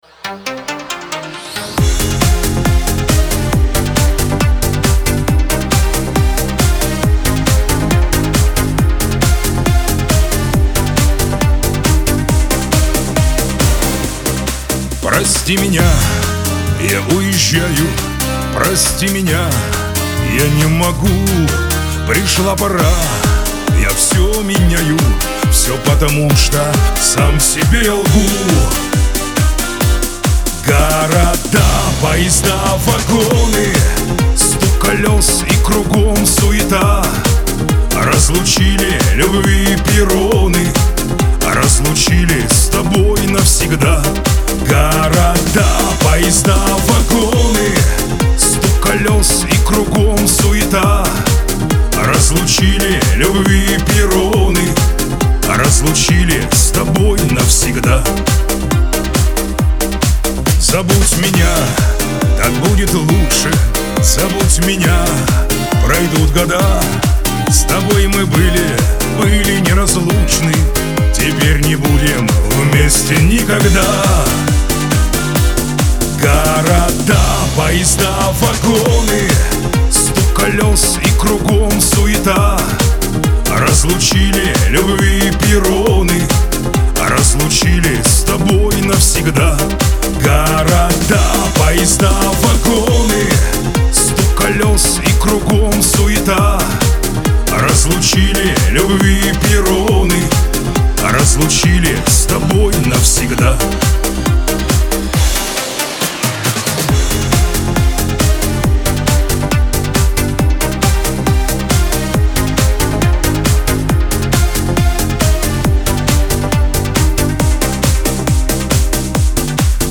Шансон
Лирика